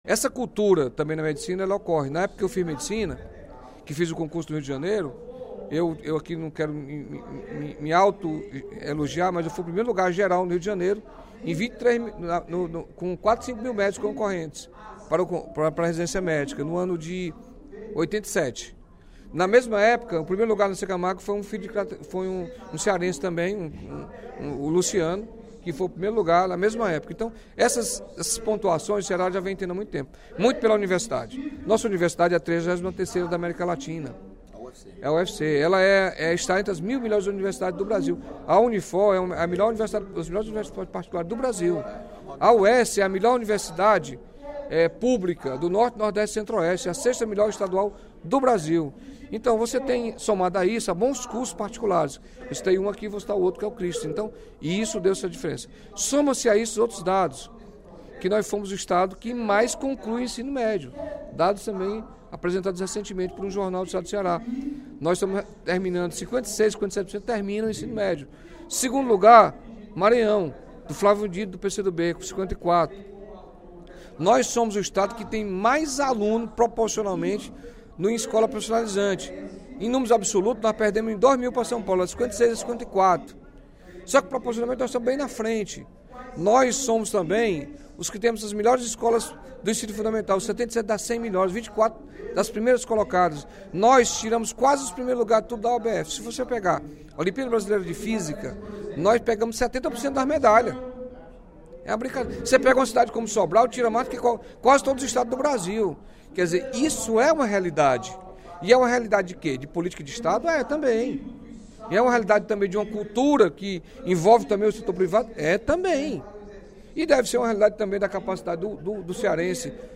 O deputado Carlos Felipe (PCdoB) exaltou, durante o primeiro expediente da sessão plenária desta quarta-feira (31/05), os resultados positivos alcançados pelo Ceará na área da educação.